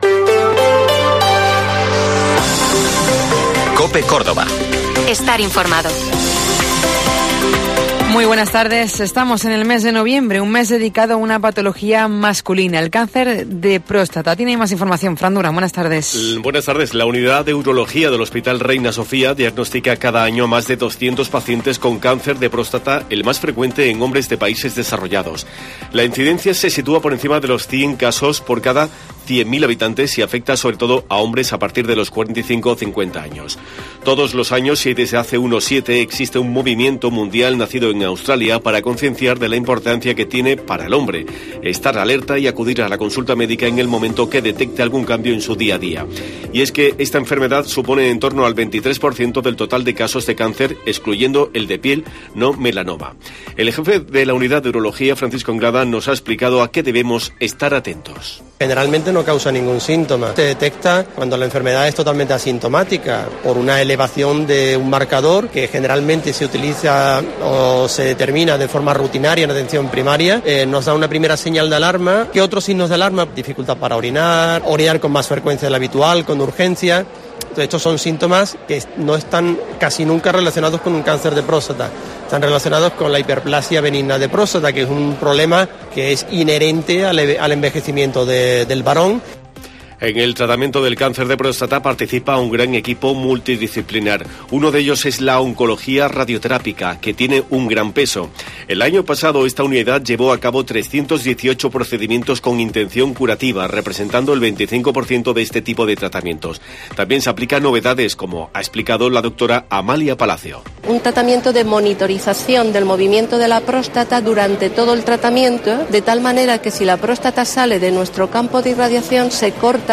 Esta es una de las noticias que abordamos hoy en el informativo Mediodía COPE.